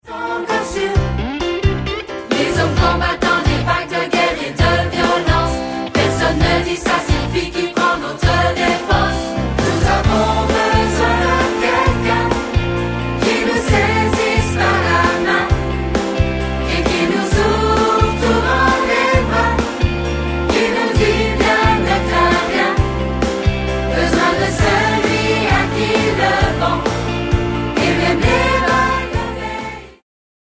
• Chants de louange pour églises, familles et autres
• Chorale et solistes